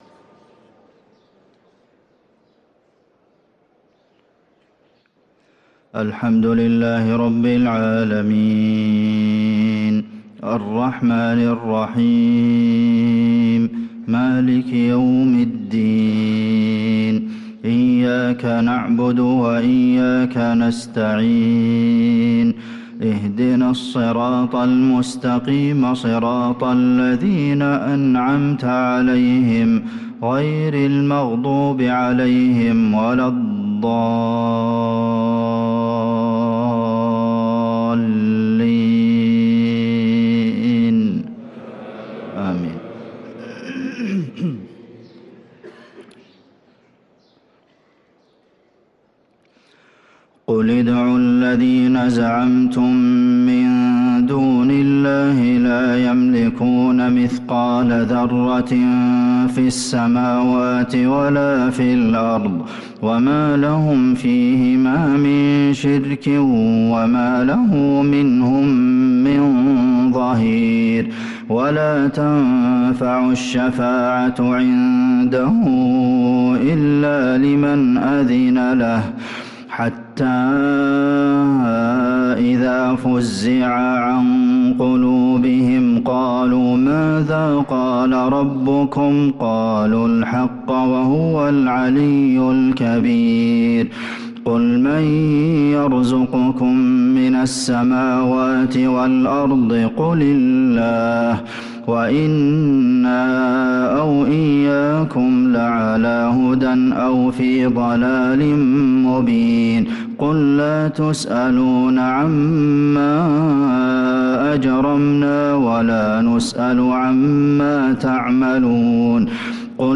صلاة الفجر للقارئ عبدالمحسن القاسم 26 رجب 1445 هـ